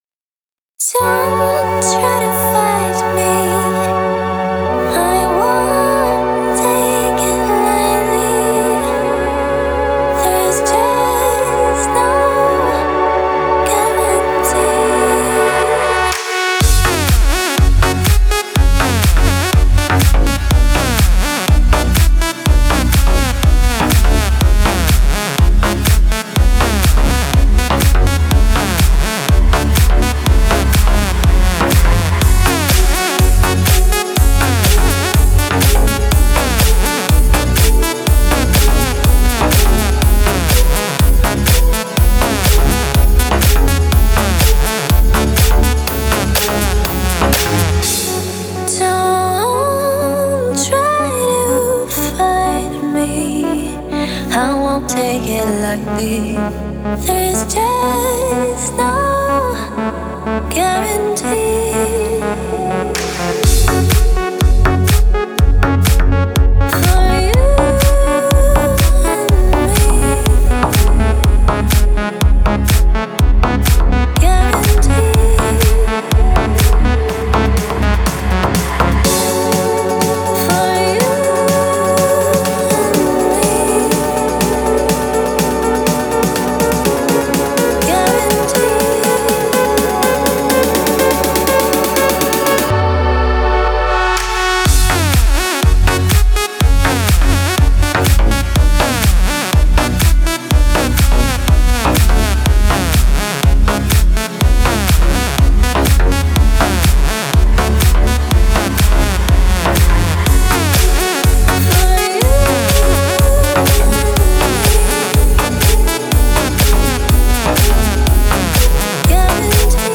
эмоциональная композиция в жанре поп-рок